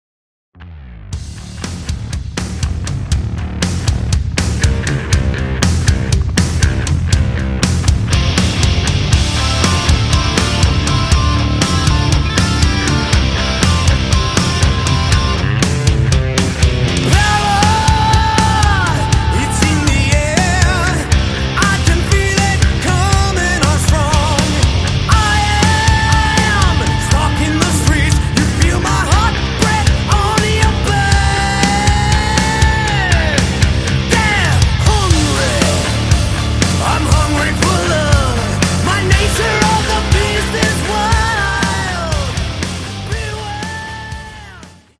Category: Power AOR / melodic hard rock